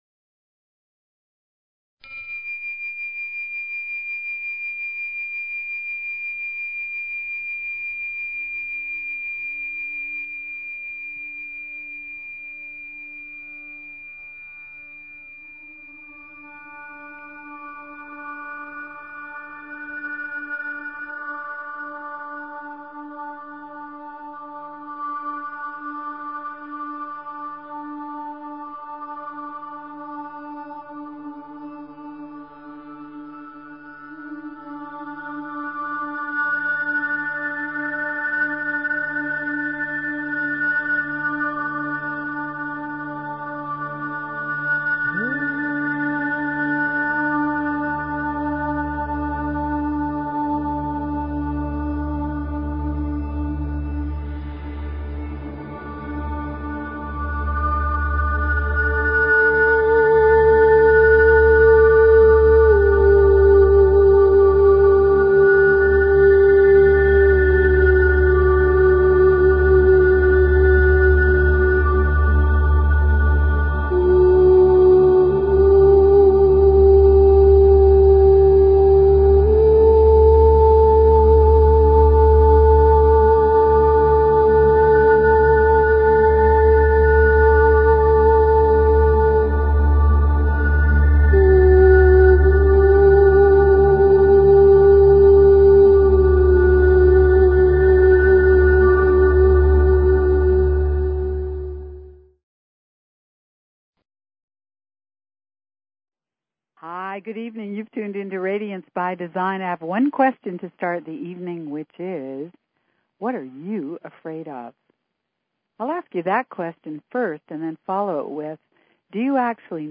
Talk Show Episode, Audio Podcast, Radiance_by_Design and Courtesy of BBS Radio on , show guests , about , categorized as
This is a call in show so call in!